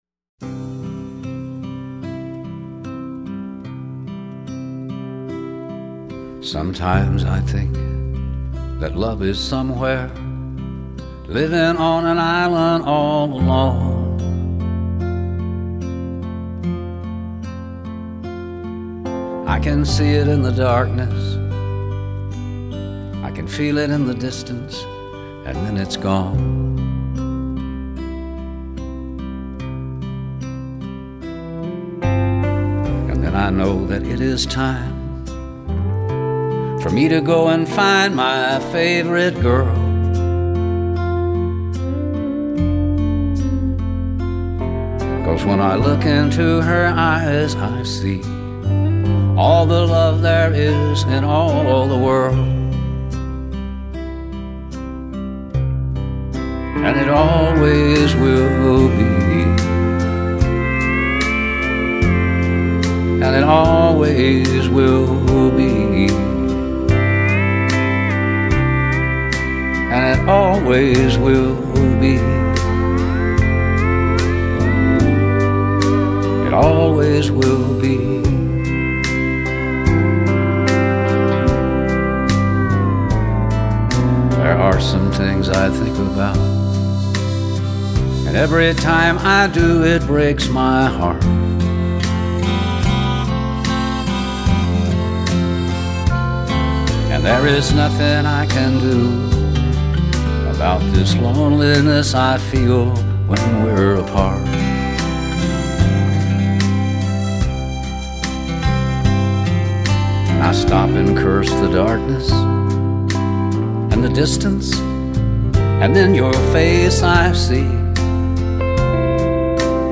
专辑类别：Country